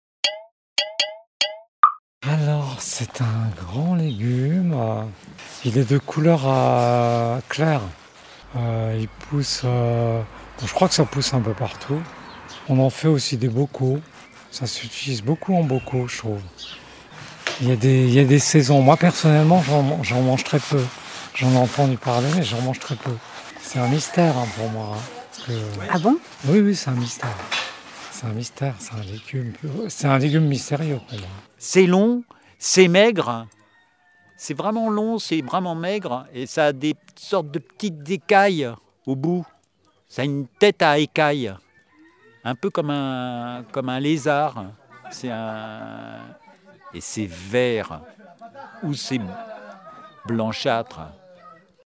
…des devinettes sonores